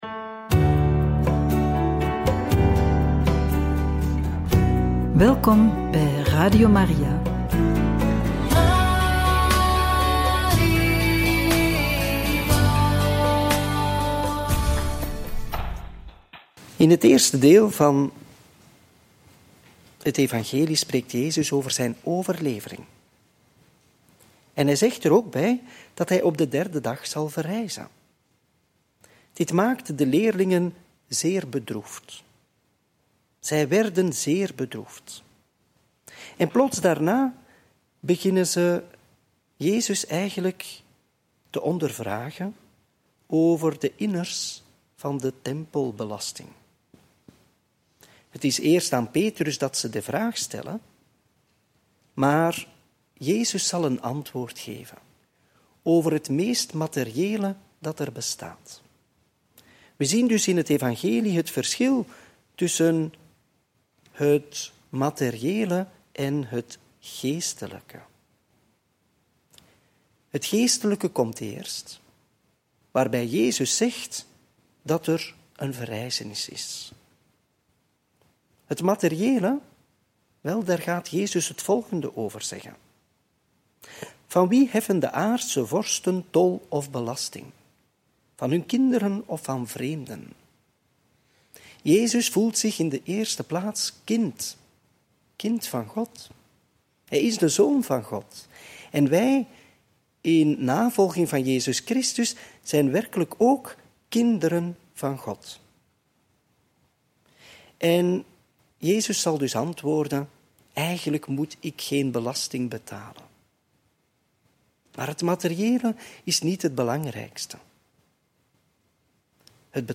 Homilie bij het Evangelie op maandag 12 augustus 2024 (Mt. 17, 22-27)